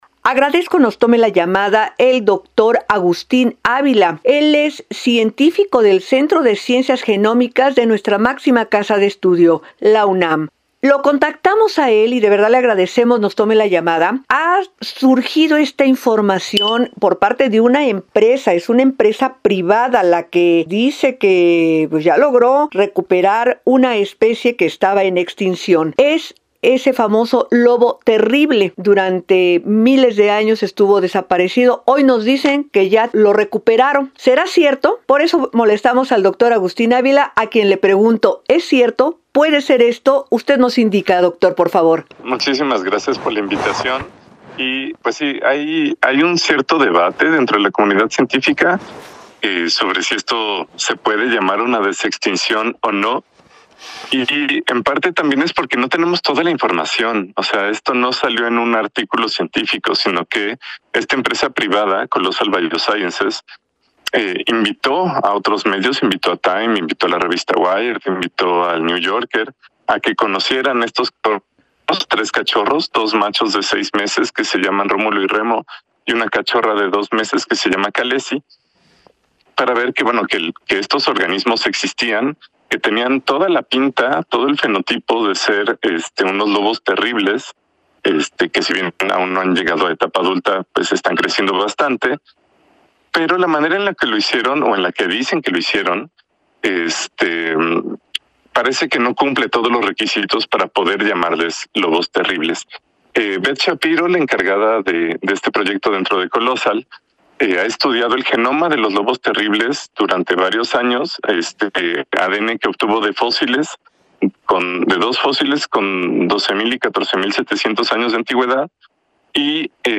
20-ENTREV-LOBOS-TERRIBLES.mp3